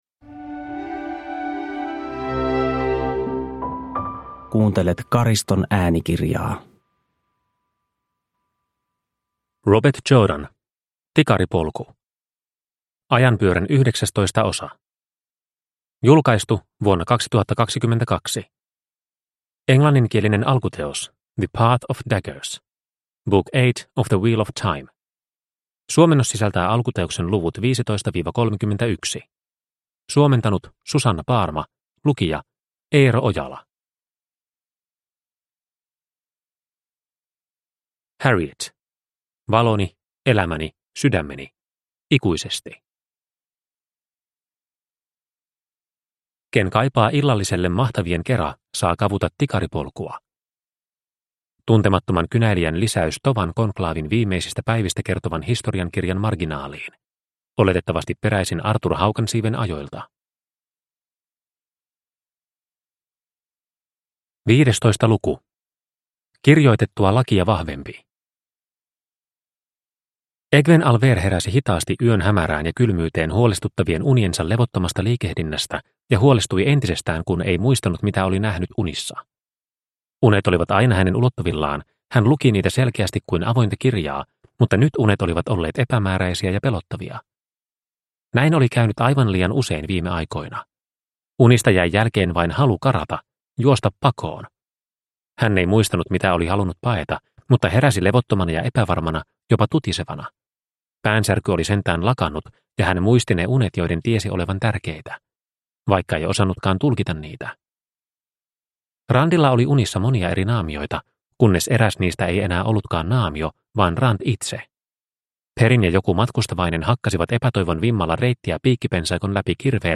Tikaripolku – Ljudbok – Laddas ner